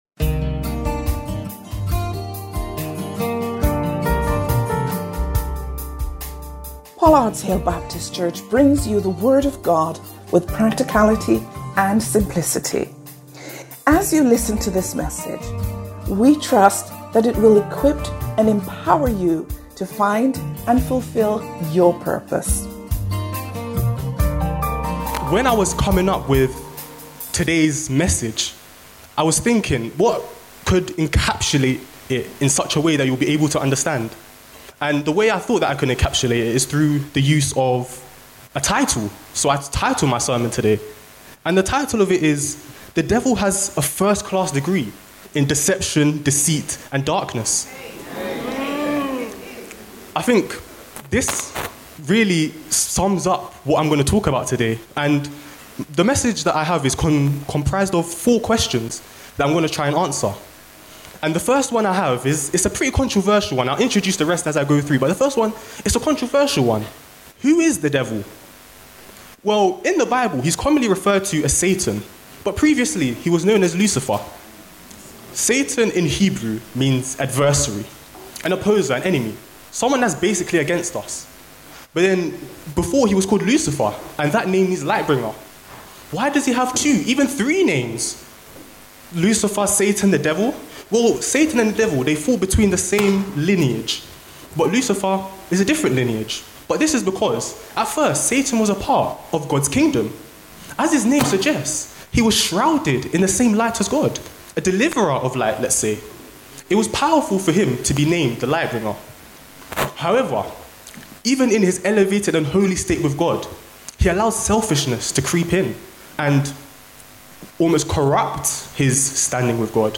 Watch this service and more on our YouTube channel – CLICK HERE
Join us once again, for a fresh perspective, as our young people bring their unique talents and passions to every aspect of the worship service, stepping up and take the lead!